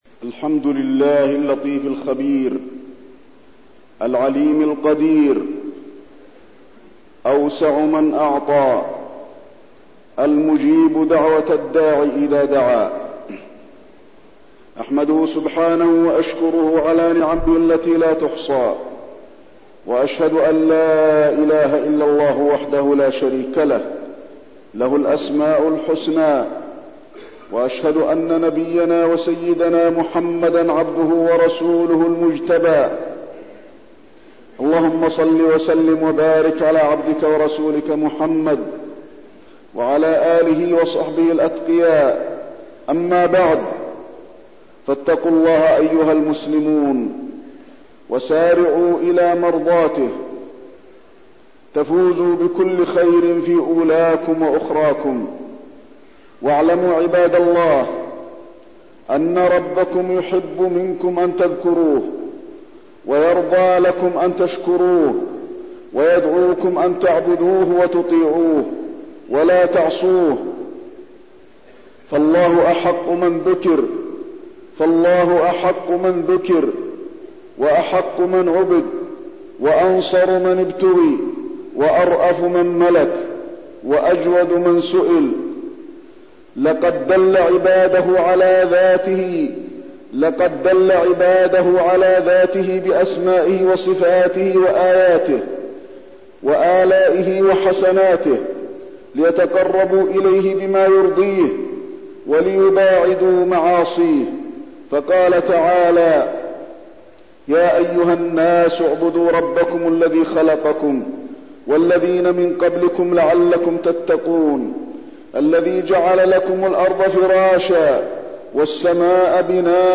تاريخ النشر ٢٧ جمادى الأولى ١٤١١ هـ المكان: المسجد النبوي الشيخ: فضيلة الشيخ د. علي بن عبدالرحمن الحذيفي فضيلة الشيخ د. علي بن عبدالرحمن الحذيفي التحذير من الفتن